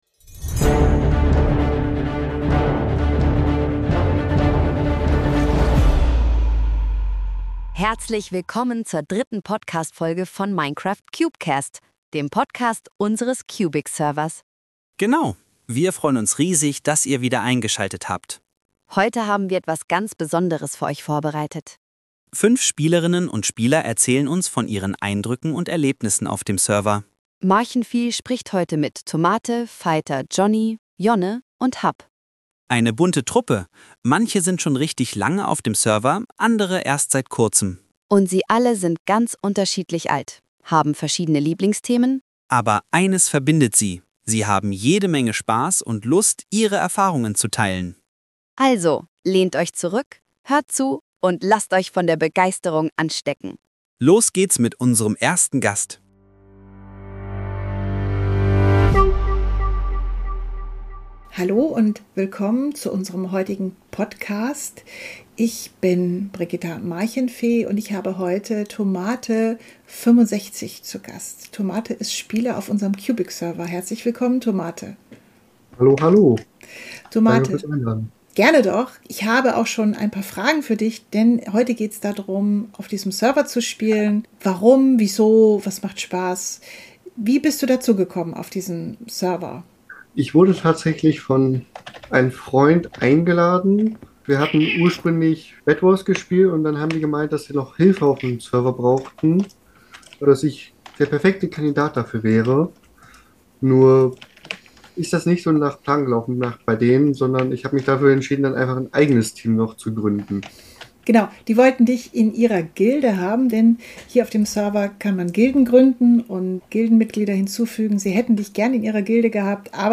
Wundert euch nicht, zwischendurch quakt mal meine liebste Katze rein.